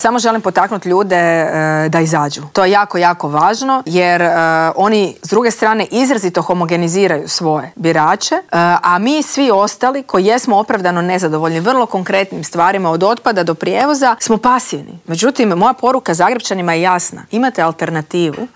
U specijalnom izbornom Intervjuu tjedna Media servisa ugostili smo nezavisnu kandidatkinju za gradonačelnicu Grada Zagreba Mariju Selak Raspudić s kojom smo razgovarali o problemima Zagrepčana.